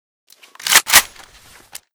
saiga_unjam.ogg